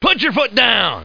1 channel
foot.mp3